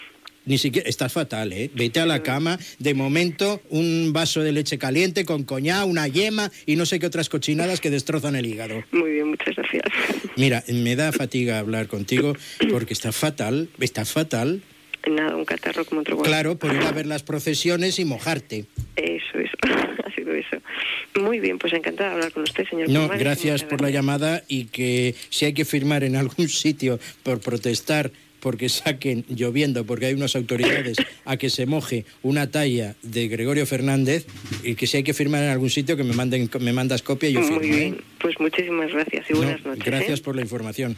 Indicatiu de la ràdio, careta, benvinguda, comentari sobre el programa televisiu "Tómbola".
Trucada d'una oient sobre les processons de Setmana Santa a Valladolid.